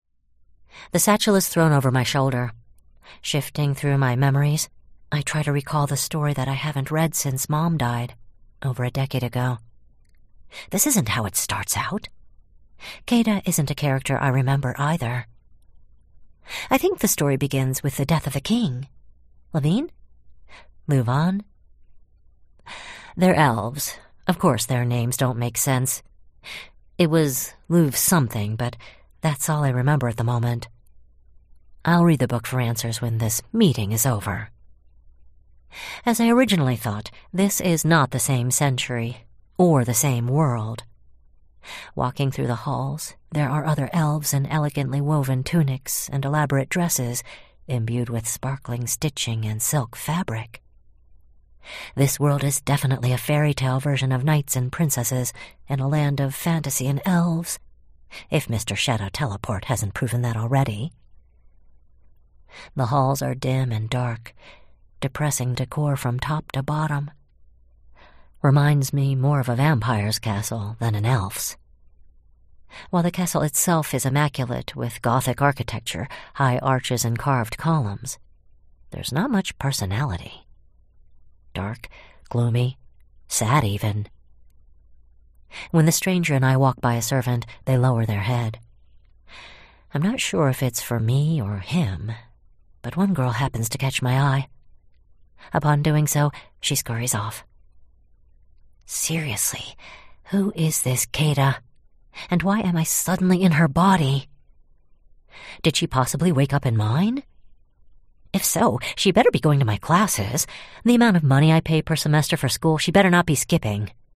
[PZG] Reborn as the Villain's Lackey, Vol. 01 (Audiobook) [Recorded Books]